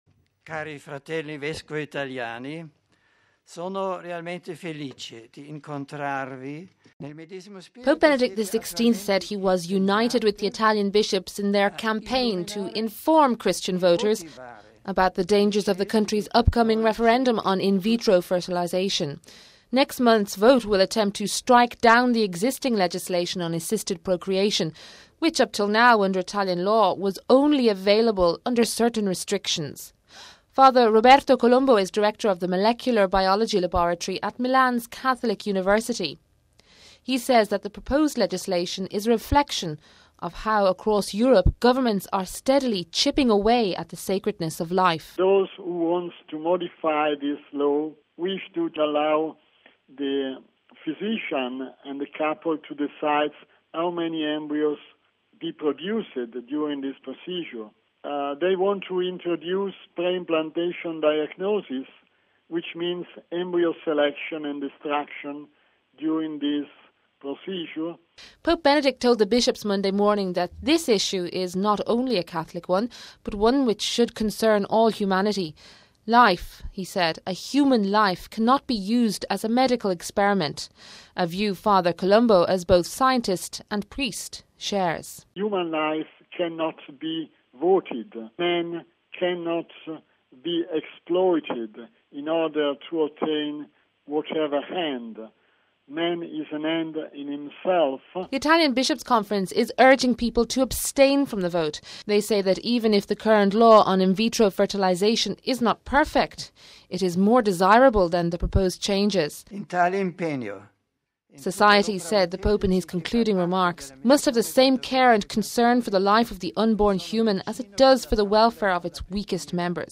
(30 May 05 - RV) Pope Benedict XVI spoke to the Italian Bishop's Conference in the Vatican on Monday. Among the issues he touched on was Italy's June 12-13 referendum on in vitro fertilization.